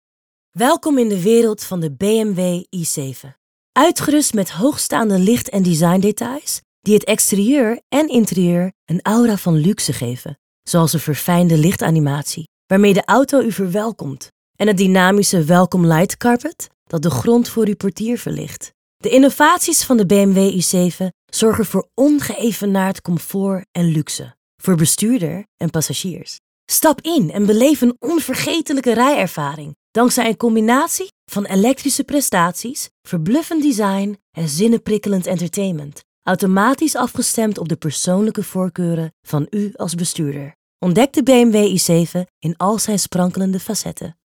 Voice Demo